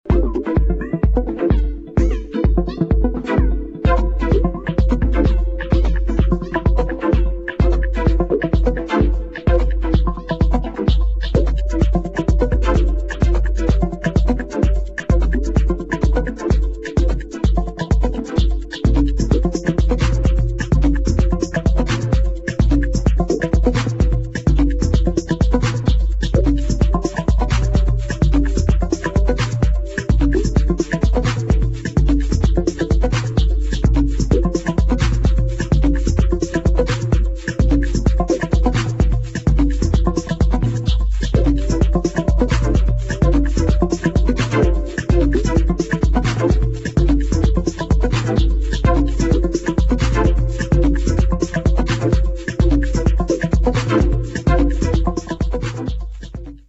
[ TECHNO | TECH HOUSE | MINIMAL ]